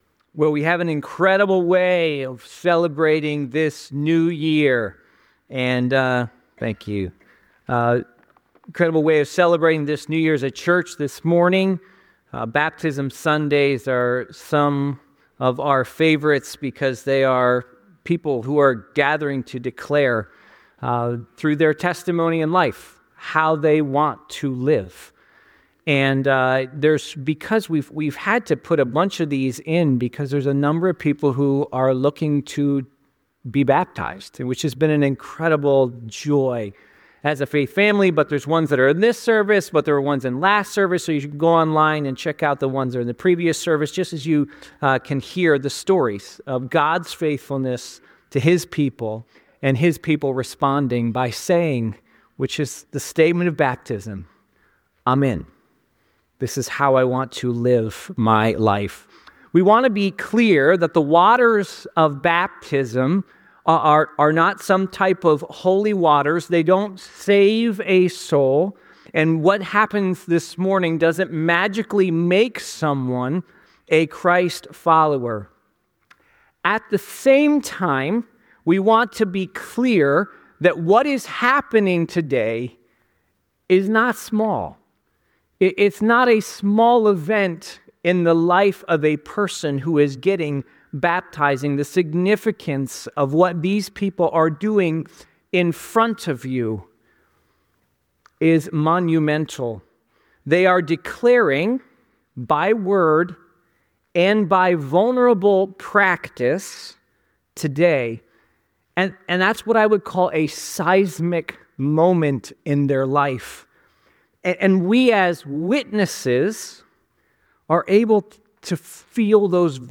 Baptism Sunday - January 2026
Fellowship's New Year celebration centered around Baptism Sunday, highlighting the transformative journeys of several congregation members. The celebrants share their personal testimonies, marked by struggles with faith, addiction, mental health, and significant life changes before finding redemption and commitment to Christ.